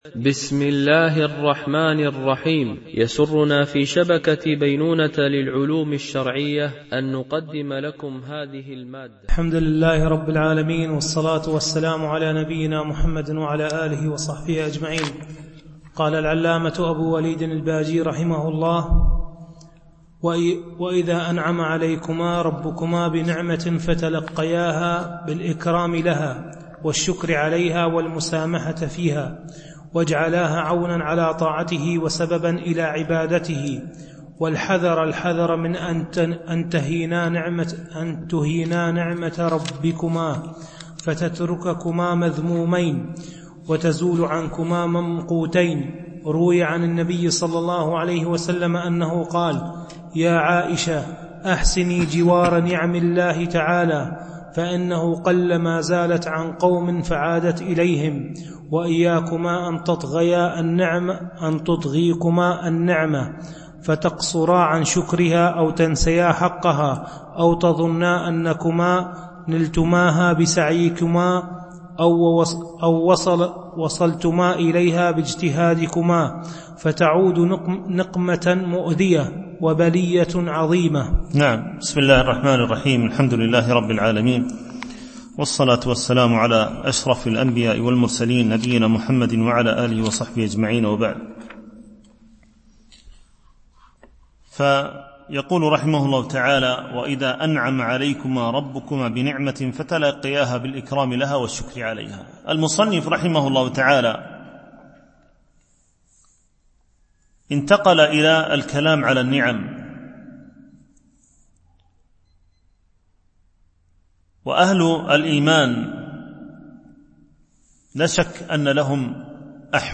شرح النصيحة الولدية ـ الدرس 17